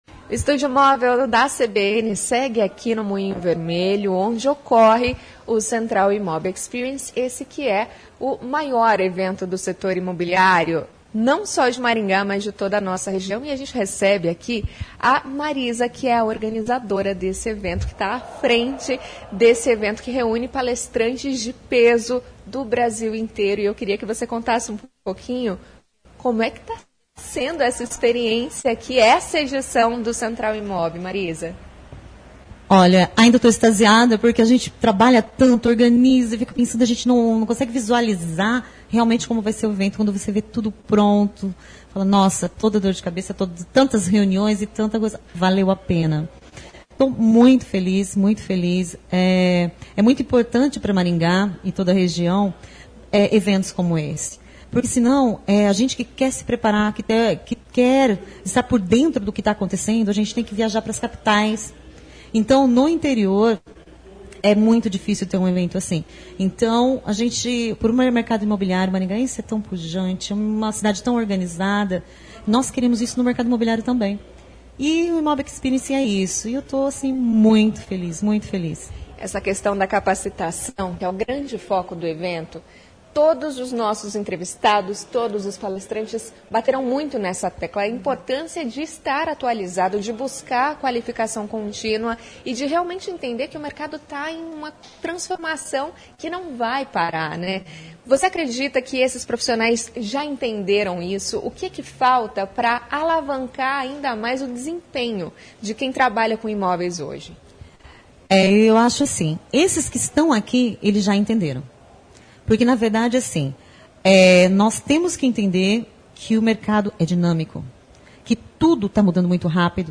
Estúdio Móvel CBN